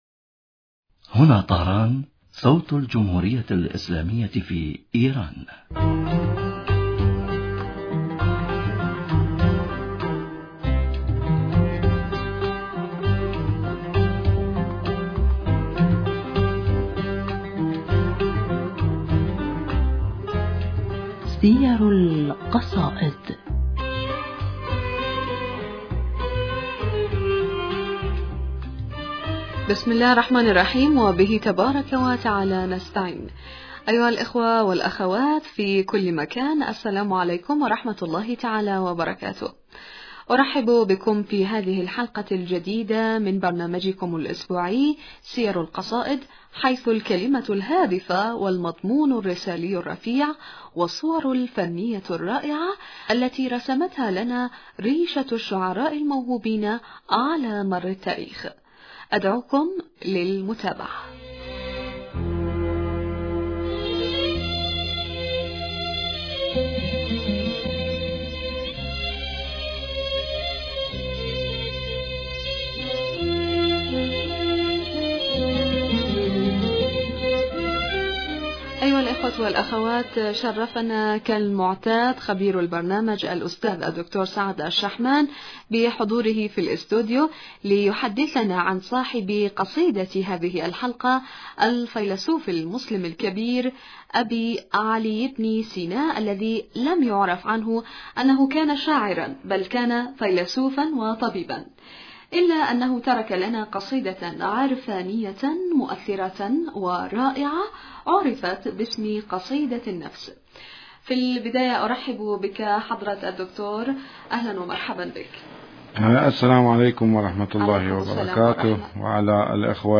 المحاورة: بسم الله الرحمن الرحيم وبه تبارك وتعالى نستعين، أيها الأخوة والأخوات في كل مكان السلام عليكم ورحمة الله تعالى وبركاته.